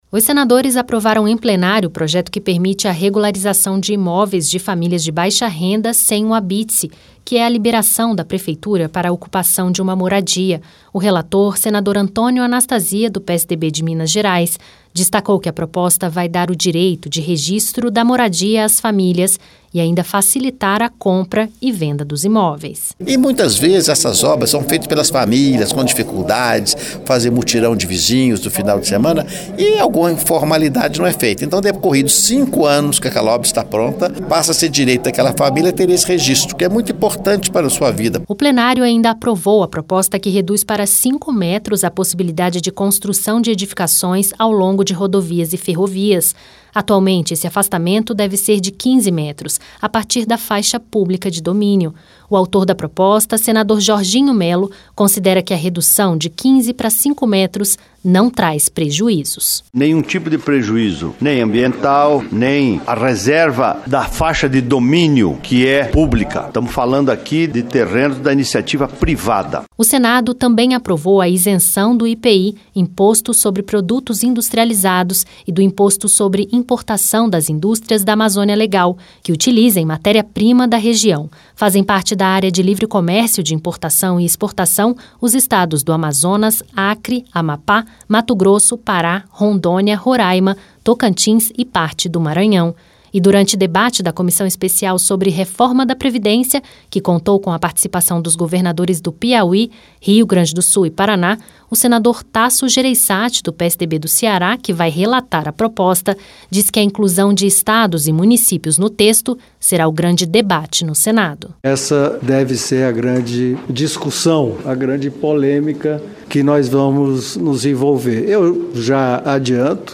Registro de imóveis para famílias de baixa renda, isenção de IPI para indústrias da Amazônia Legal, Reforma da Previdência e criminalização do caixa dois em campanhas eleitorais. A semana no Senado teve votações e debates sobre esses e outros temas. Ouça mais detalhes na reportagem